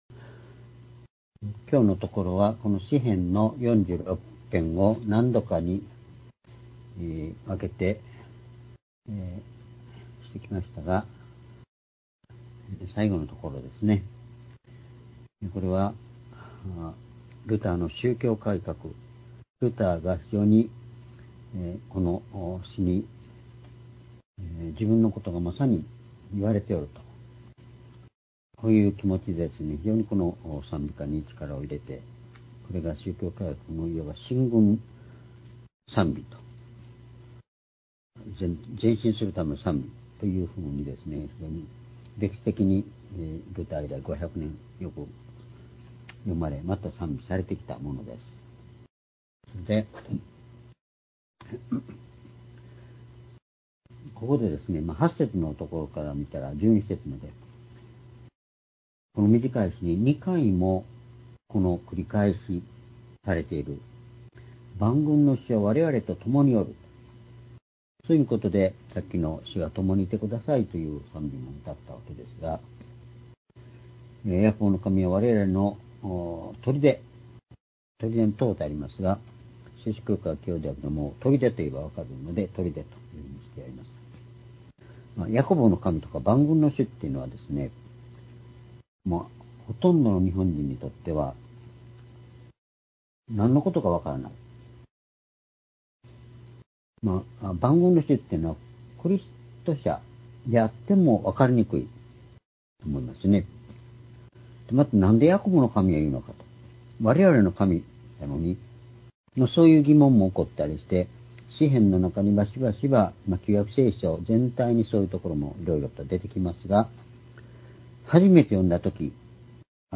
（主日・夕拝）礼拝日時 ２０２５年２月４日（夕拝） 聖書講話箇所 「万軍の主はわれらの砦」 詩編４６編９節～１２節 ※視聴できない場合は をクリックしてください。